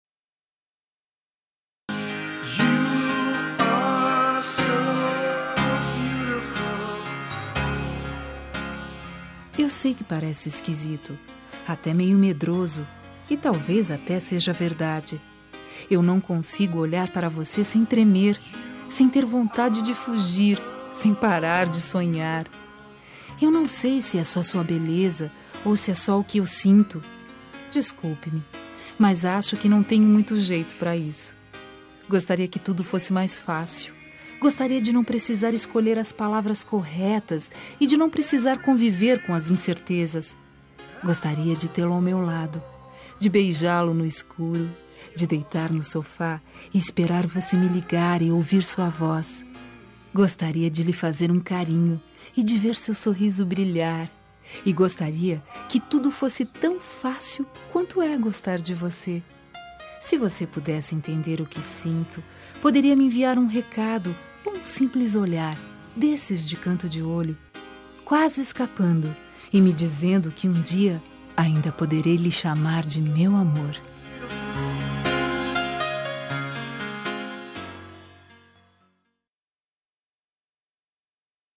Telemensagem de Paquera – Voz Feminina – Cód: 2149
Paquera fem meu amor 2149.mp3